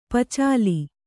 ♪ pacāli